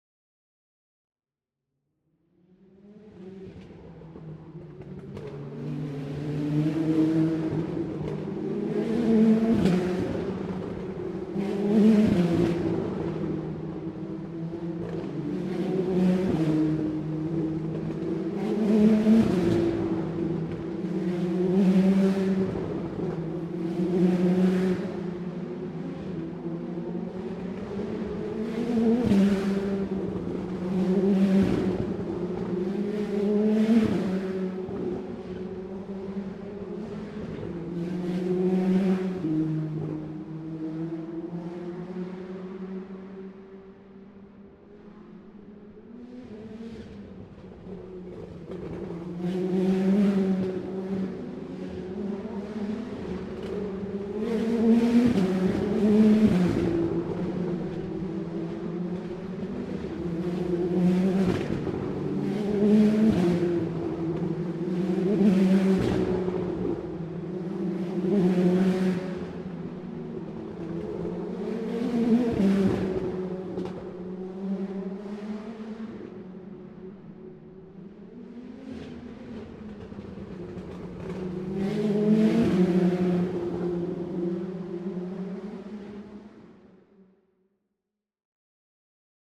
Toronto Indy Lights car race
The roar of high-performance engines at the Toronto Indy Lights race during qualifying at turn 9, passing by in a pack relentlessly.